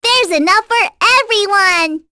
Luna-Vox_Halloween_Skill1.wav